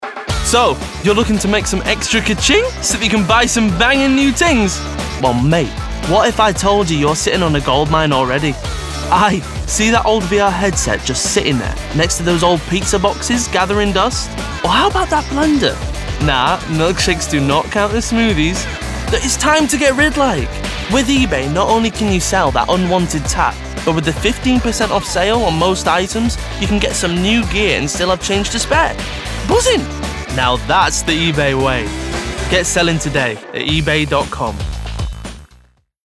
Voice Reel
Matey, Cheerful